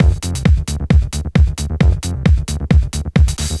hooj_102_fast_loop.wav